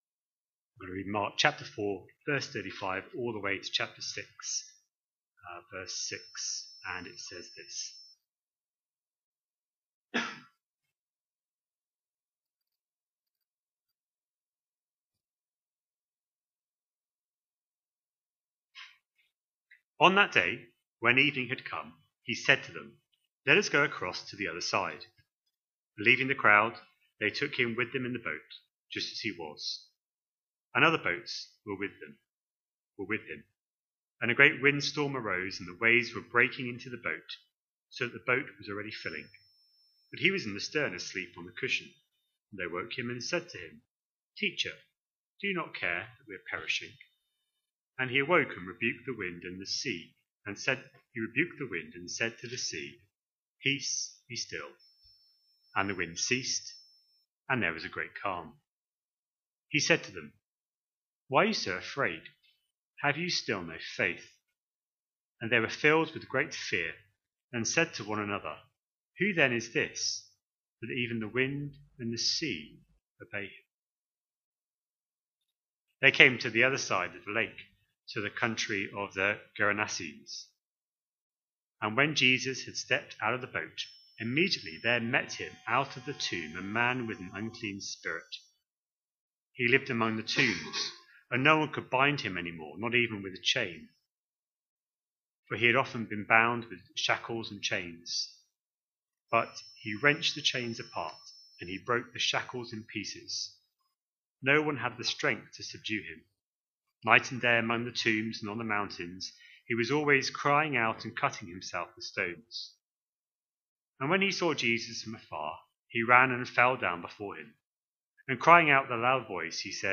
A sermon preached on 9th November, 2025, as part of our Mark 25/26 series.